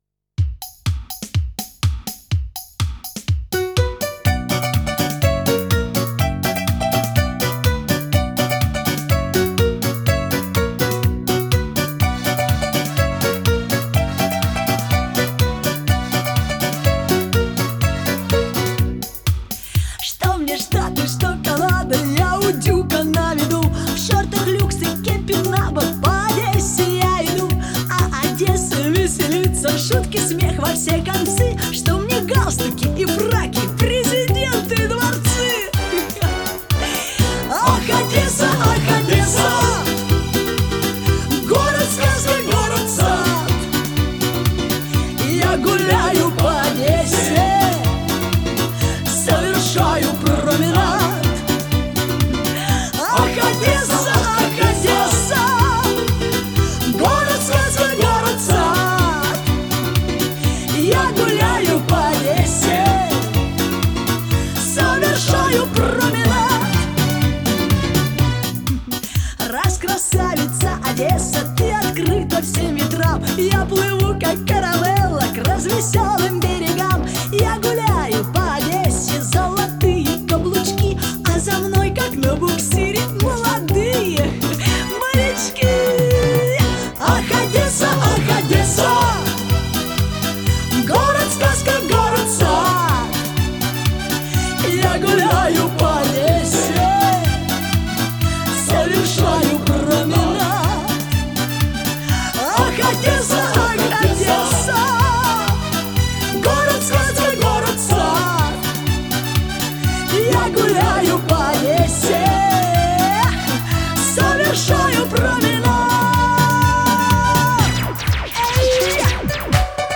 Жанр: Russian Pop, Pop
Стиль: Russian Pop